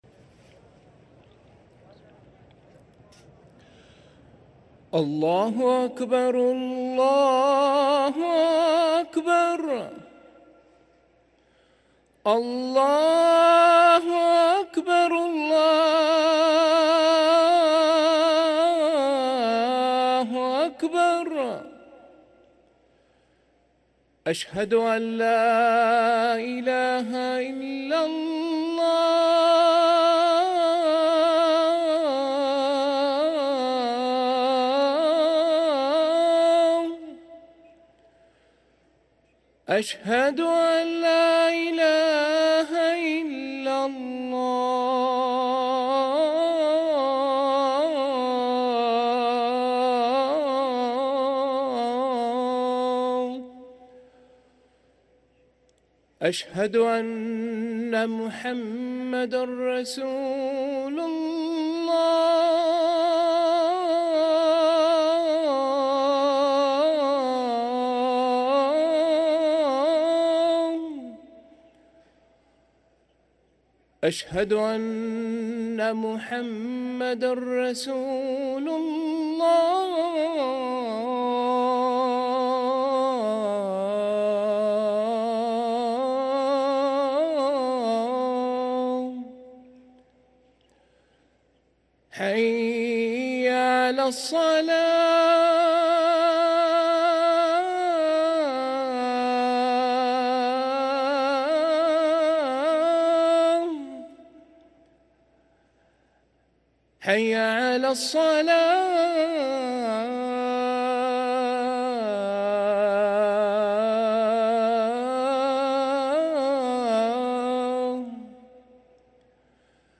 أذان العصر
ركن الأذان